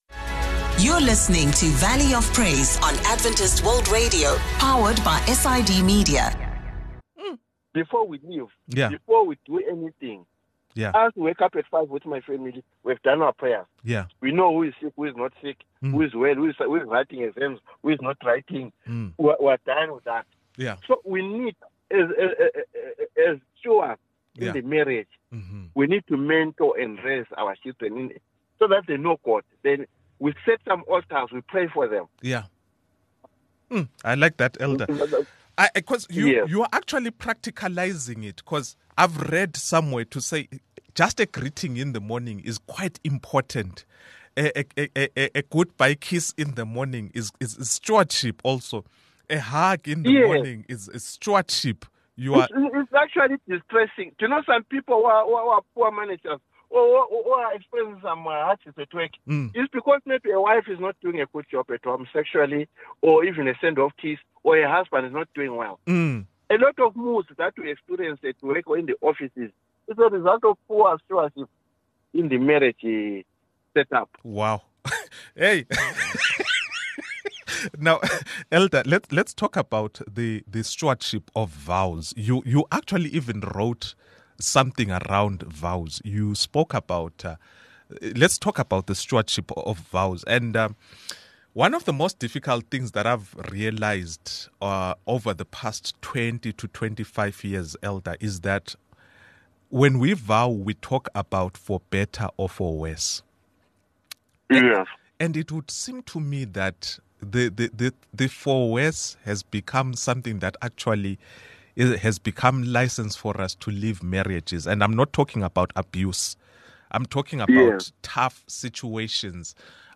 In this episode, we interview a family life counselor